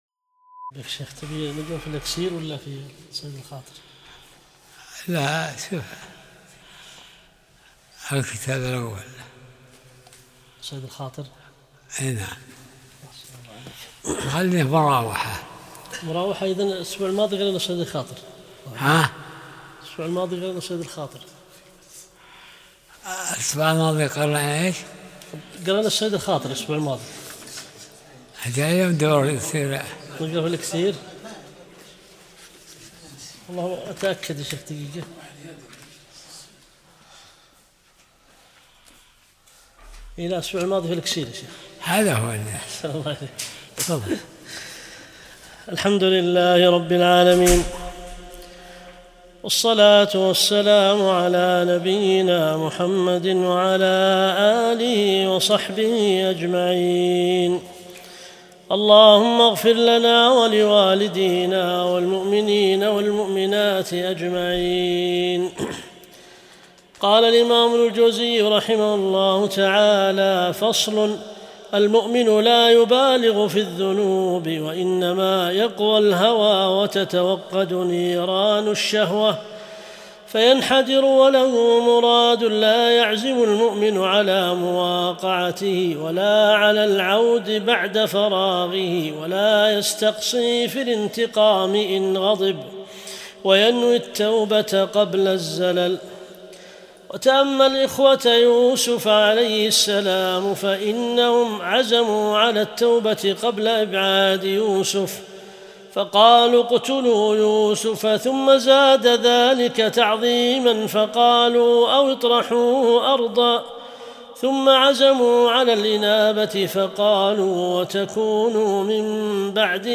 درس الأربعاء 66